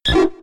Cri de Roucool K.O. dans Pokémon X et Y.